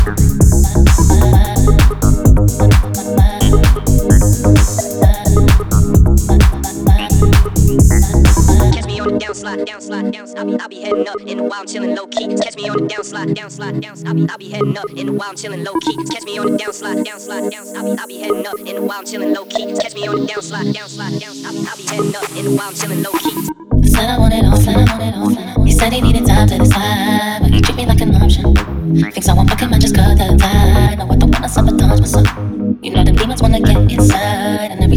Dance House
Жанр: Танцевальные / Хаус